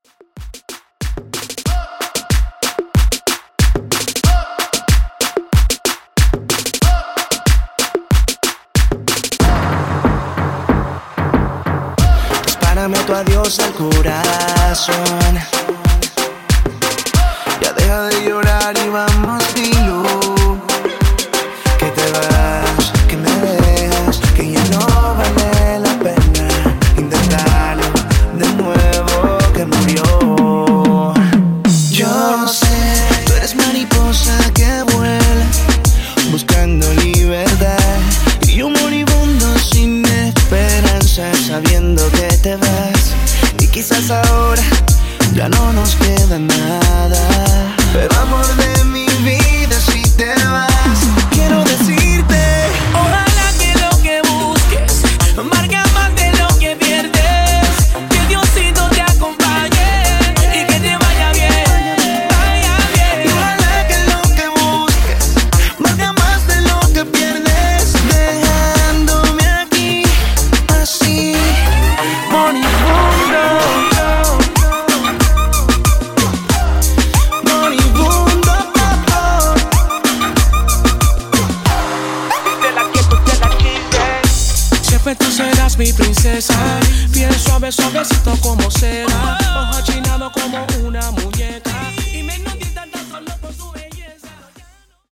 In-Outro Reton)Date Added